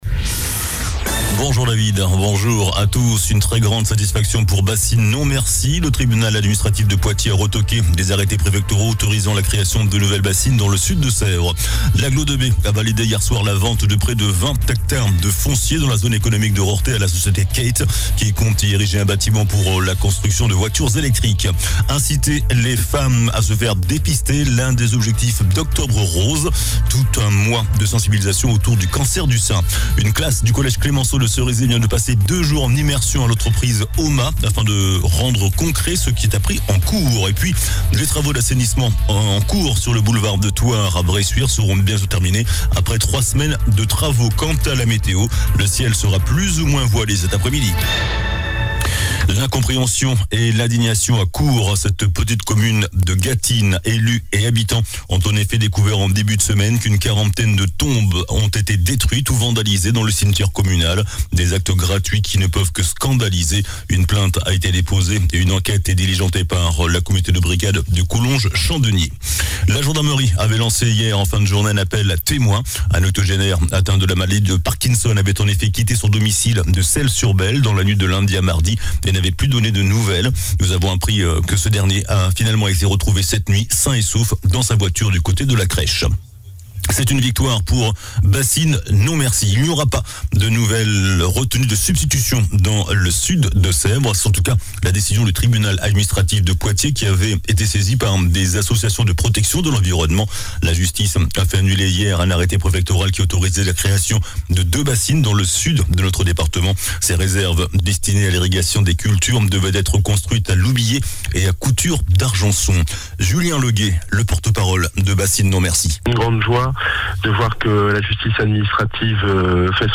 JOURNAL DU MERCREDI 04 OCTOBRE ( MIDI )